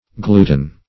Gluten \Glu"ten\, n. [L., glue: cf. F. gluten.